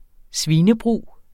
Udtale [ ˈsviːnə- ]